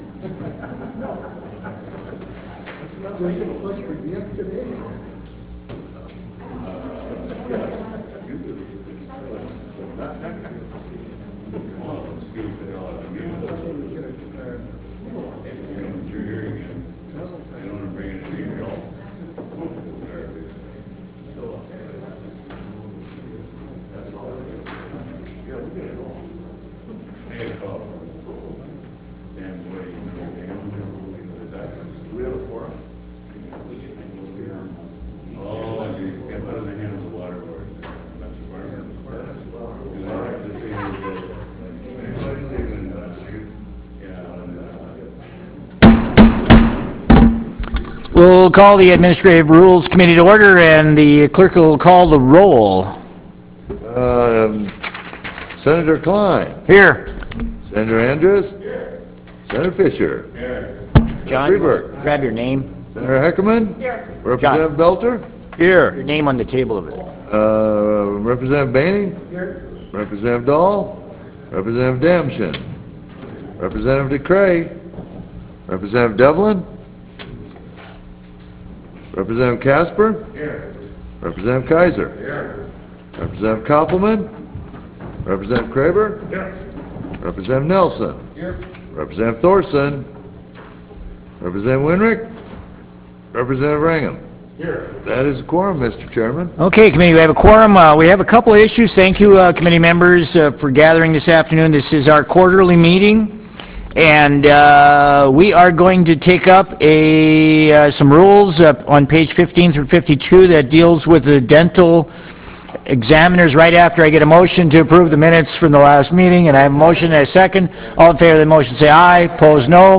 Brynhild Haugland Room State Capitol Bismarck, ND United States